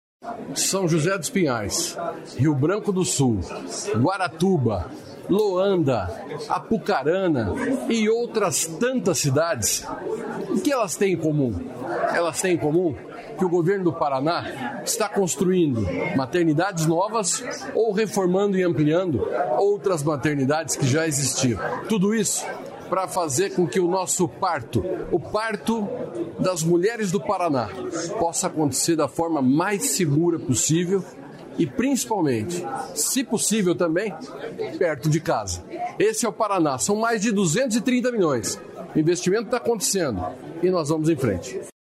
Sonora do secretário da Saúde, Beto Preto, sobre as maternidades em obra